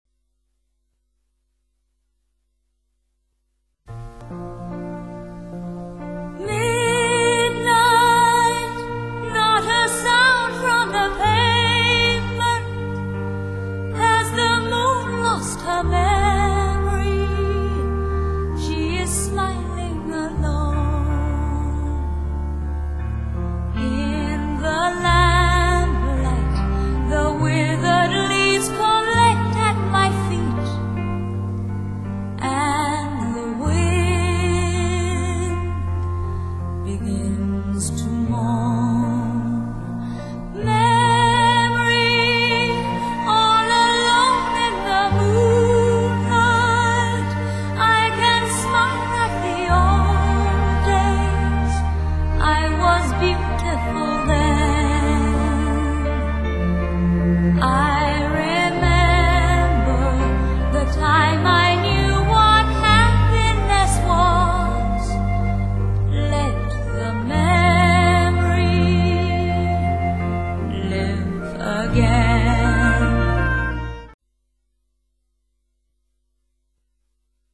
and sings this memorable song.